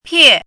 chinese-voice - 汉字语音库
pie4.mp3